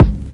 Closed Hats
dadahhh_kick.wav